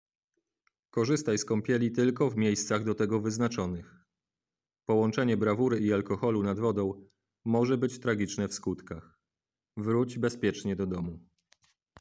Komunikaty, które będą nadawane przez system nagłaśniający w radiowozach, w trakcie wizyt policjantów nad wodą: